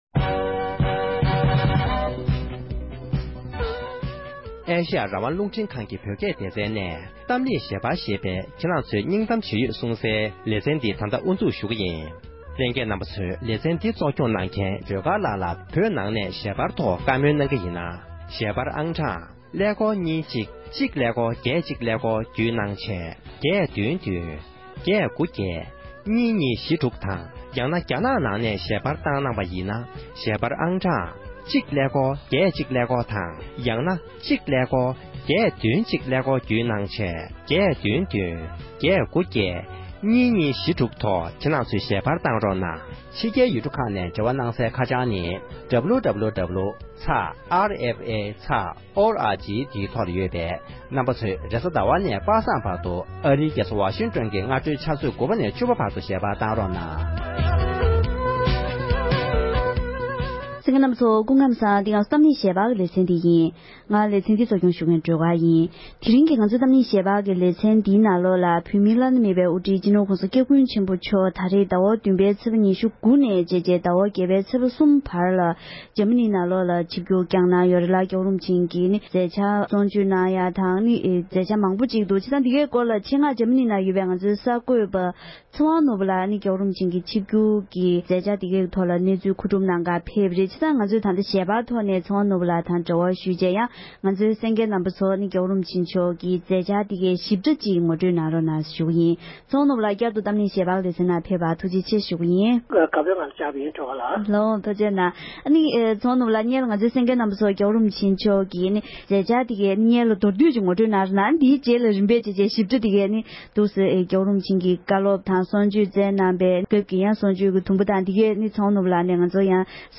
བགྲོ་གླེང་